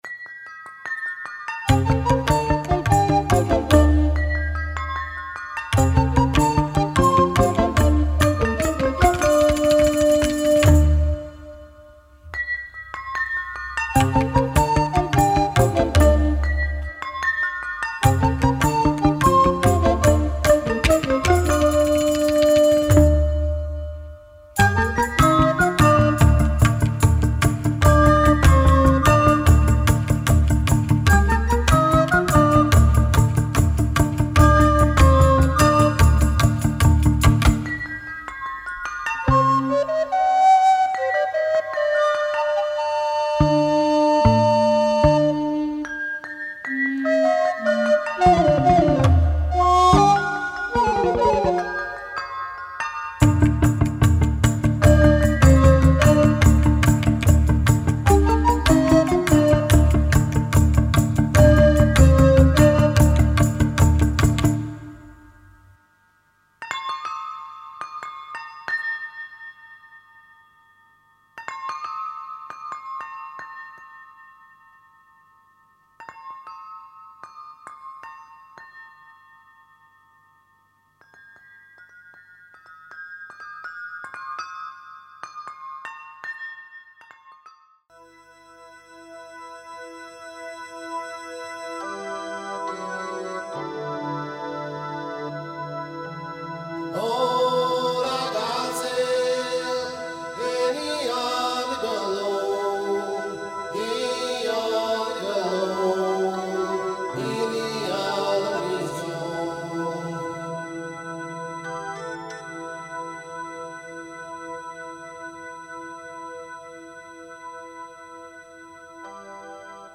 from psychedelic sitar
to minimalist afro
Indian influenced